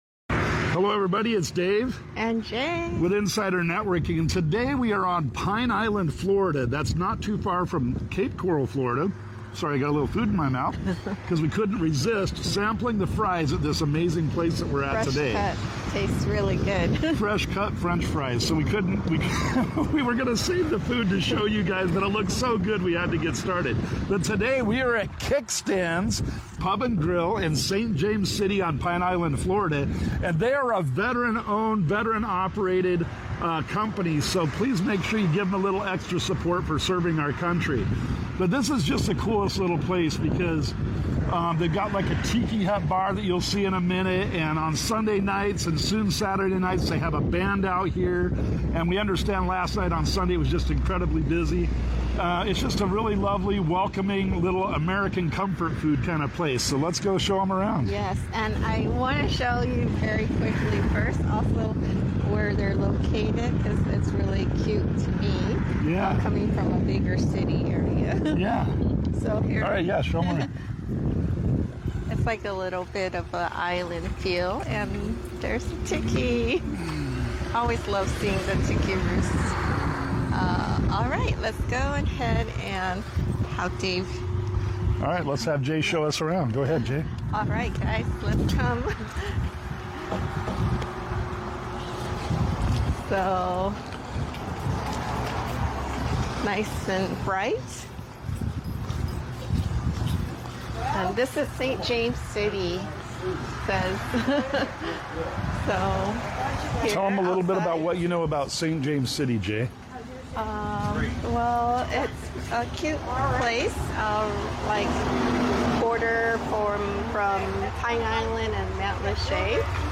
Kickstands Pub & Grub | Broadcasting LIVE